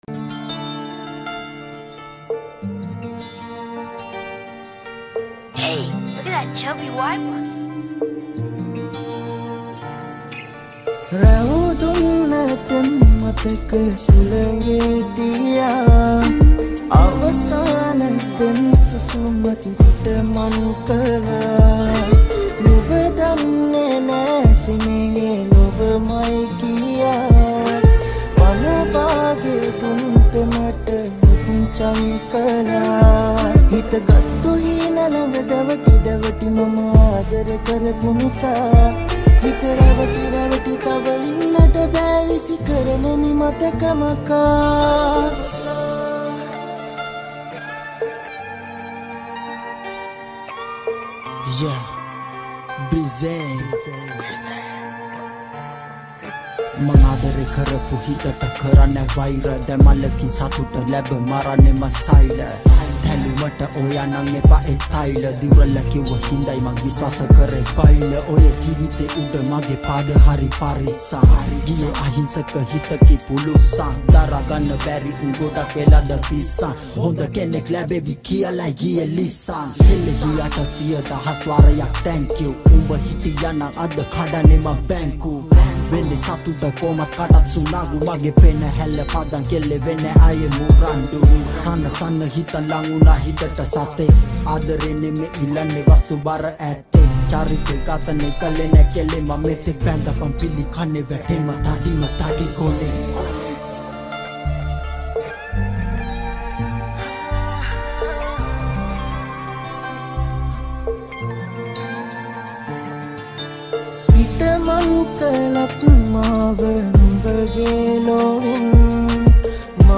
Hip Hop Wattuwa Remix Sinhala Rap Remix